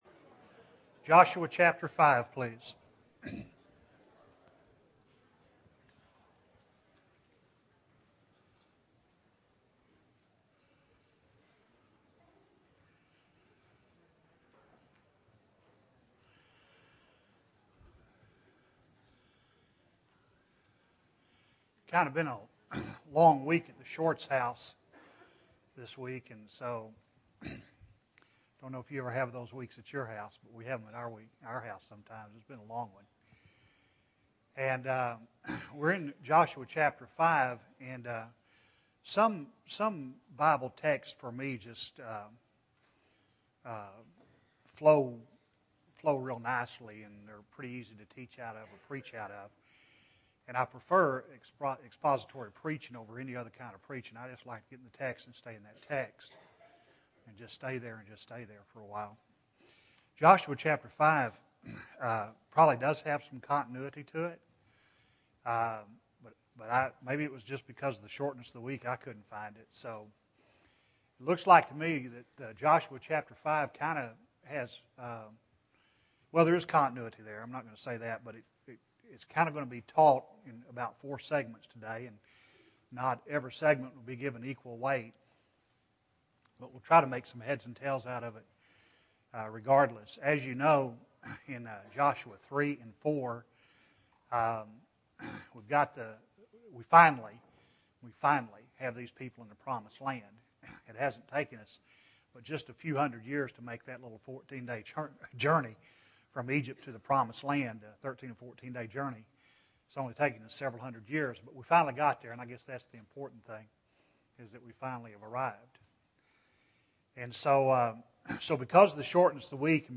Joshua 5 (5 of 14) – Bible Lesson Recording
Sunday AM Bible Class